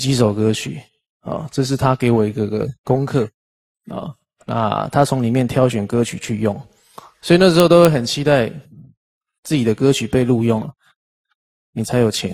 テキスト読み上げ
温かいナレーション
喜びに満ちたボーカル
入力音声（クリックしてダウンロード）